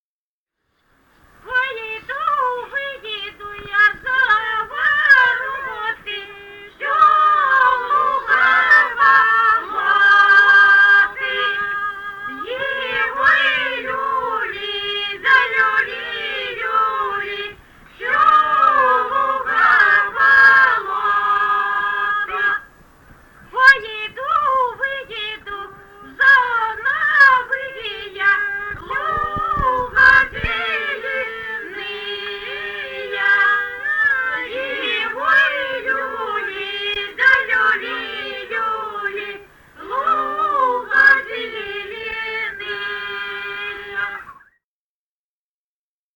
Музыкальный фольклор Климовского района 030. «Пойду, выйду я за вороты» (хороводная).
Записали участники экспедиции